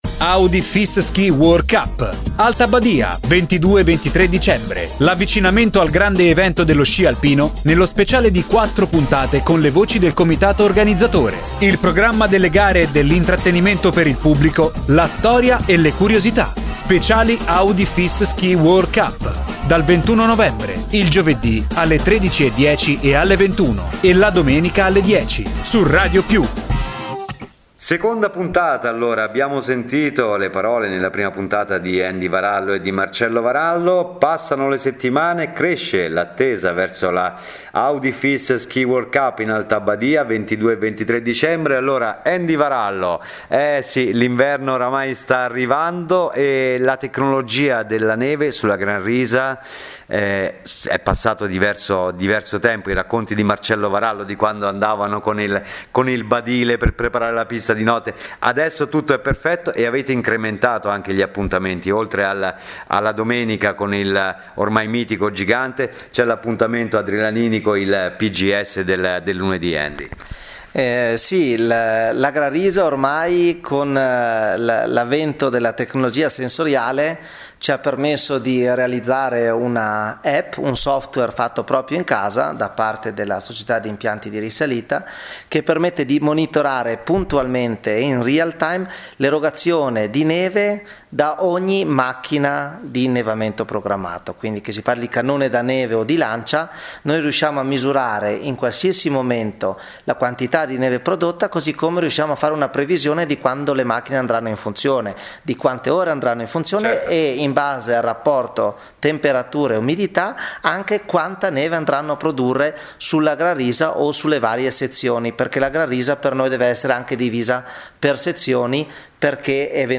Cinque speciali dedicati alla coppa del mondo di sci alpino che fara’ tappa in Alta Badia il 22 e 23 dicembre. L’avvicinamento al grande evento nella voce degli organizzatori.